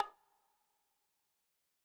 Tripp3 Perx 6 (Dro Konga 2).wav